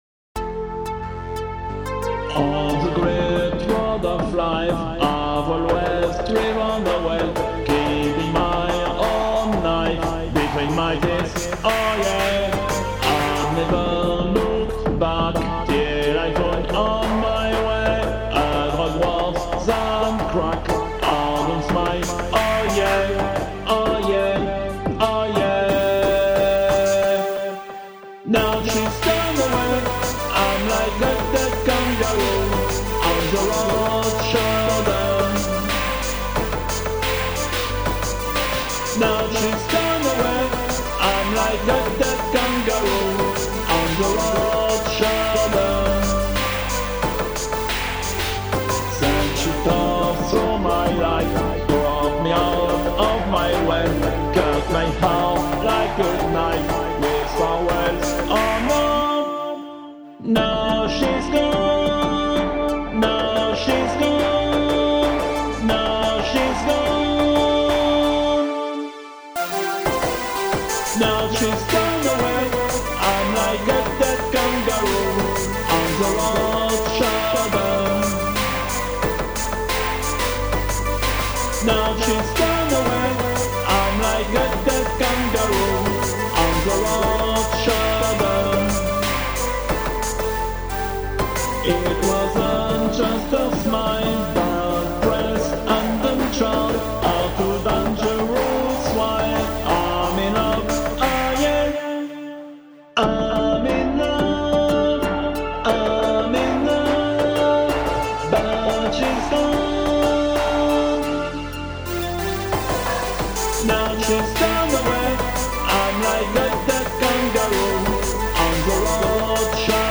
Electro-dance
triste ballade
Version complète